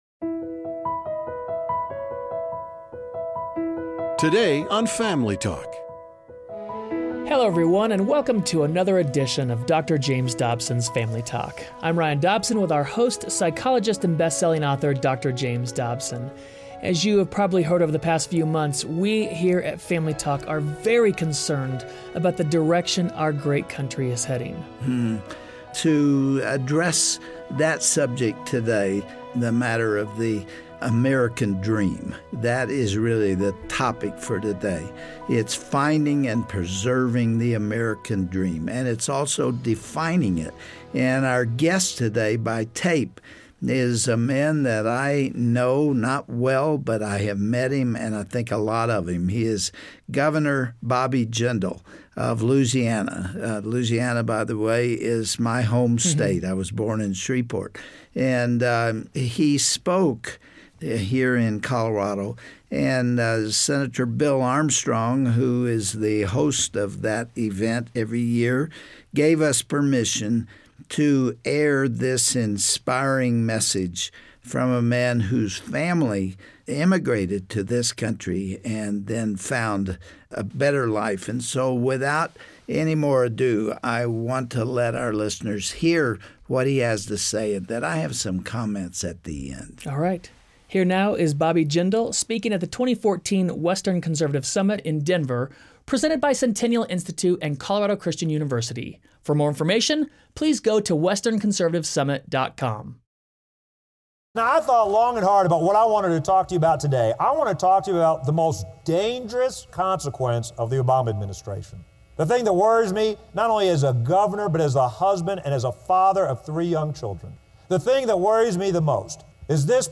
What are the characteristics that make America great? On the next edition of Family Talk Louisiana Governor Bobby Jindal shares powerful personal stories from his childhood, and discusses the impact that the Obama administration has had on the American dream.